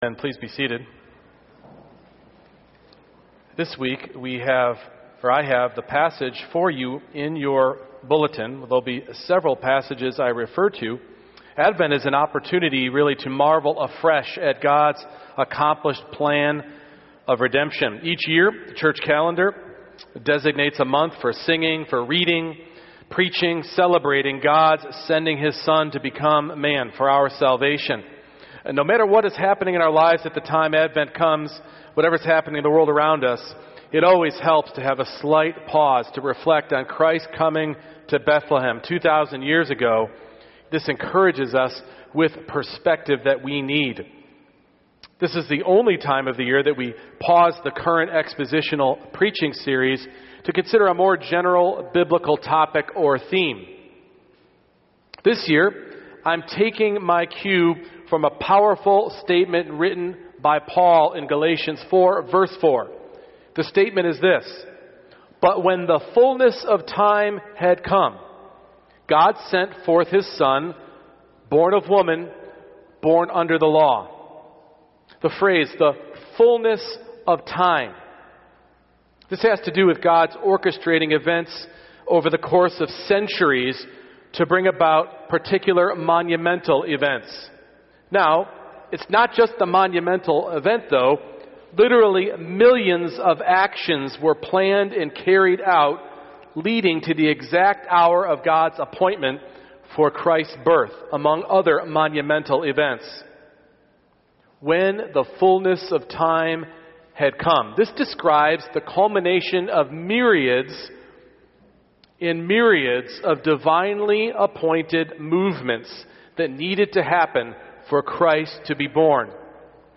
Genesis 12:1-20 Service Type: Morning Worship Abraham’s inconsistency in following God’s plan was God’s plan.